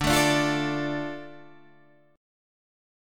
Dm chord